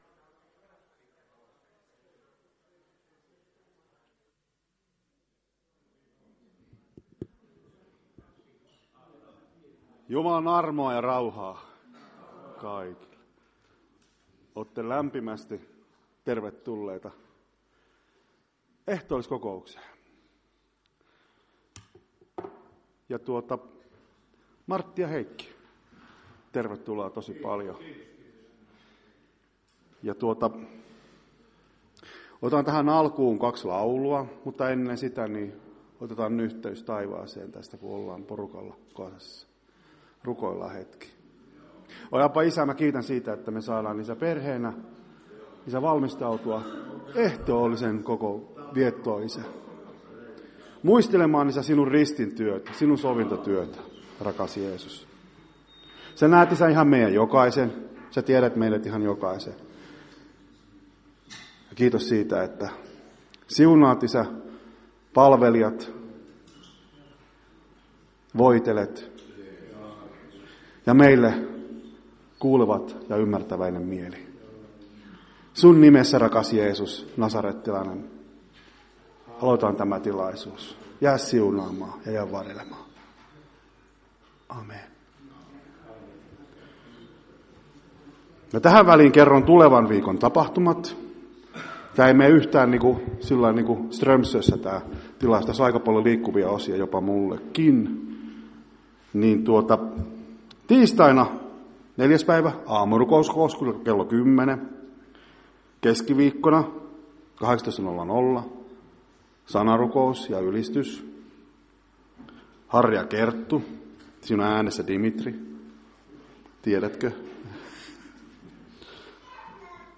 Ehtoolliskokous 2.2.2025